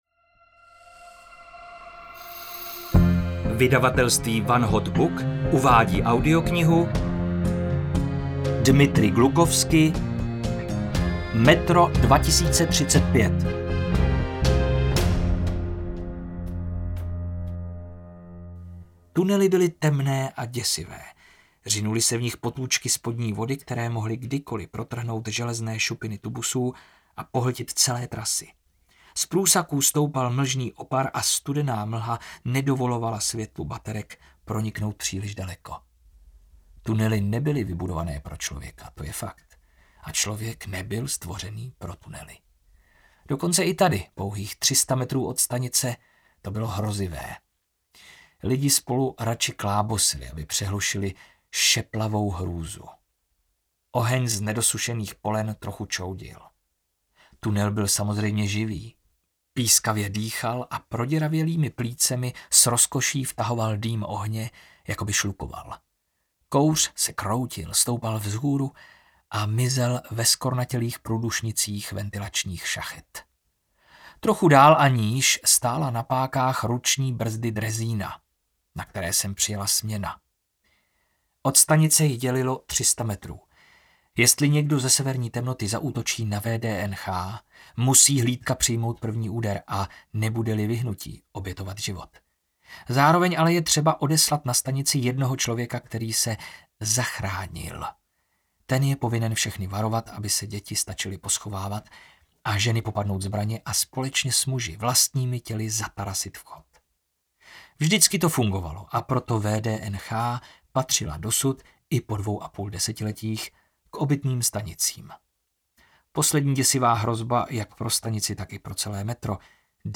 Metro 2035 audiokniha
Ukázka z knihy